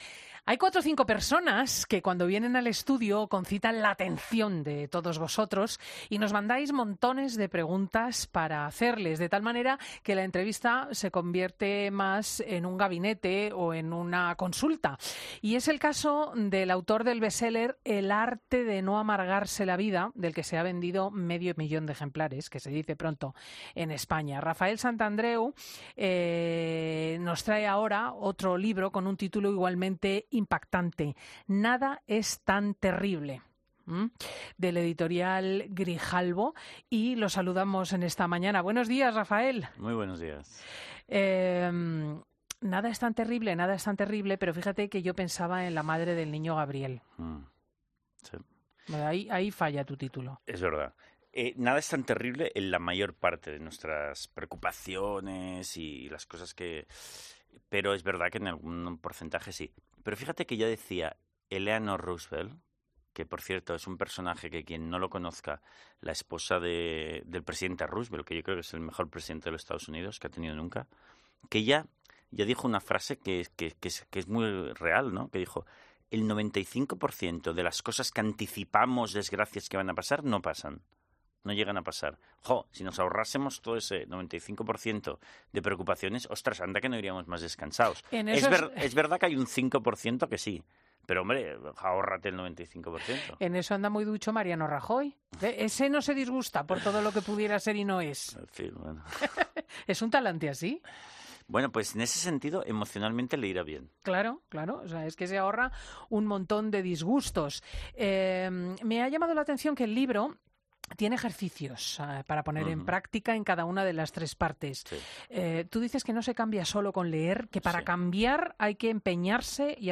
En su entrevista durante el programa 'Fin de Semana', Santandreu nos ha dado algunas de las claves para ser más felices.